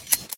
cut.ogg